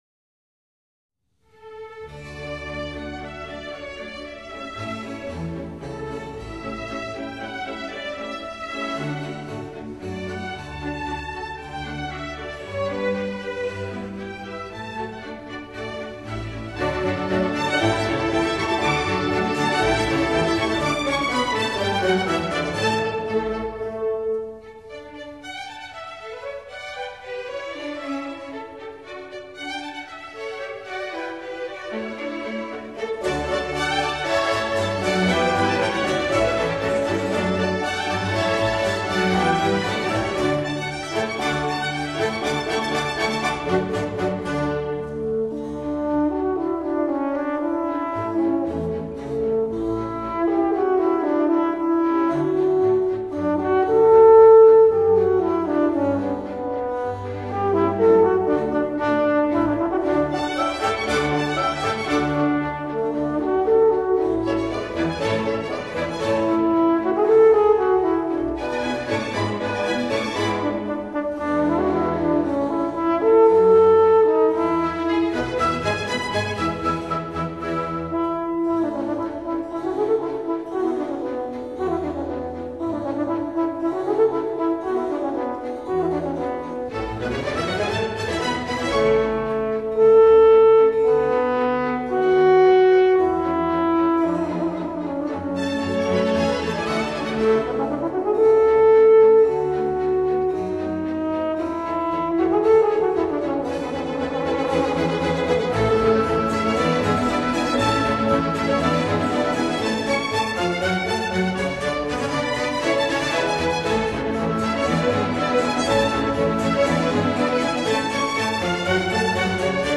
horn